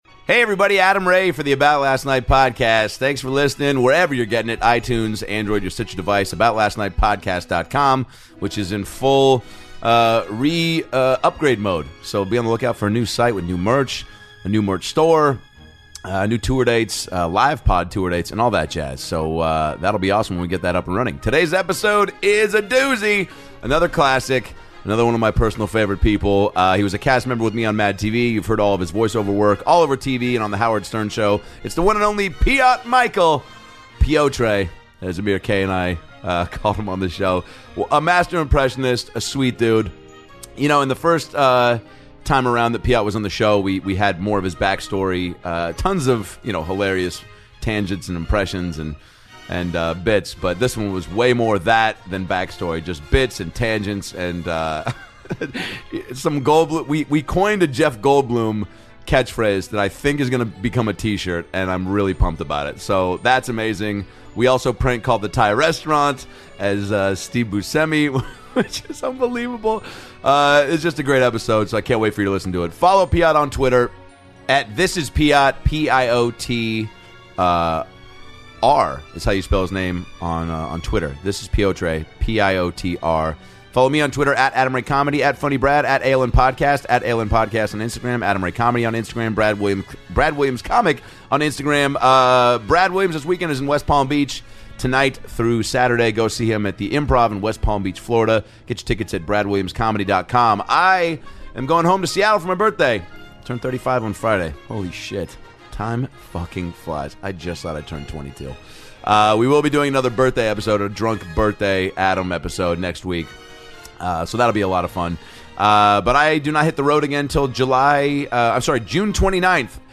returns to the podcast to do a TON of great impressions